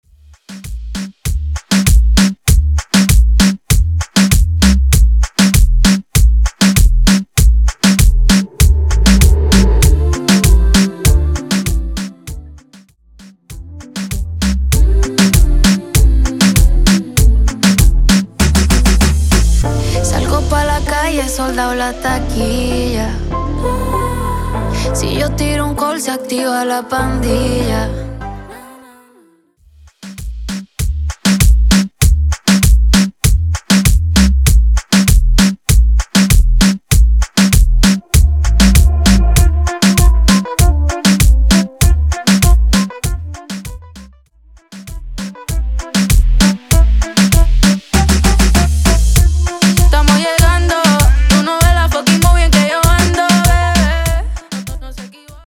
Intro Dirty, Coro Dirty